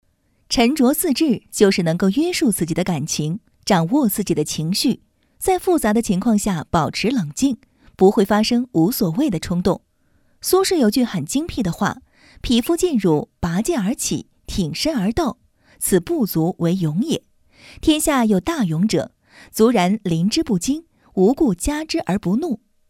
Chinese female voice over